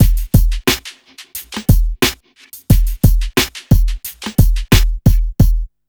Index of /90_sSampleCDs/USB Soundscan vol.34 - Burning Grunge Hip Hop [AKAI] 1CD/Partition E/04-3HHM 89